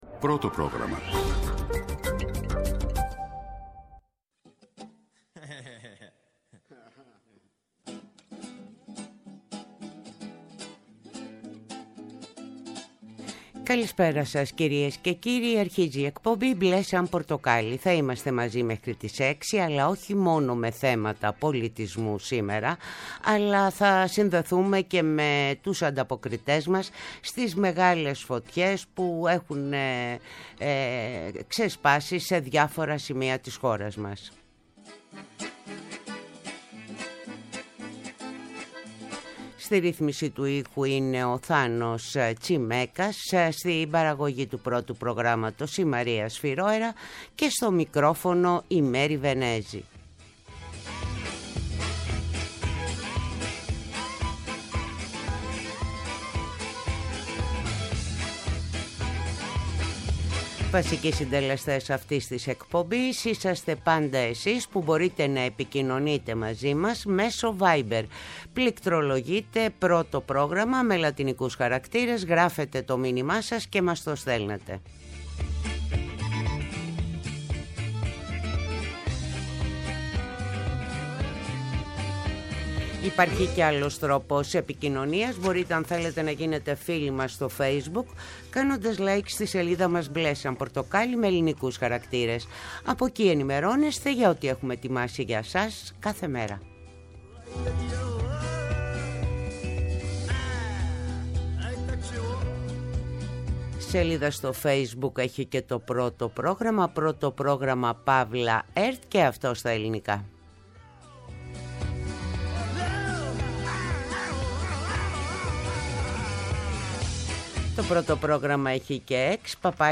Τηλεφωνικά καλεσμένοι μας είναι οι: